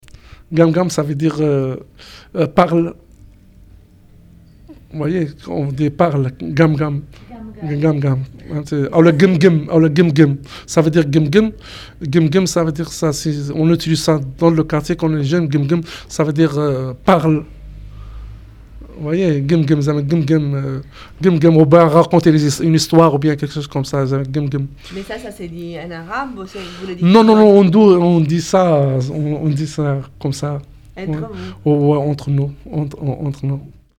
Gum gum play all stop prononciation Gum Gum ↘ explication Gum gum, ça veut dire: “Parle”.